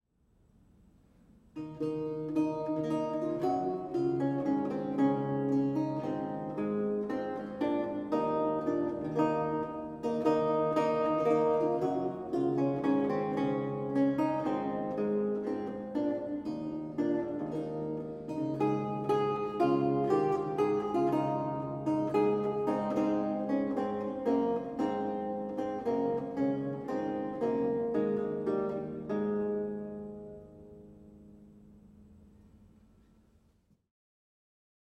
Audio recording of a lute piece
a 16th century lute music piece originally notated in lute tablature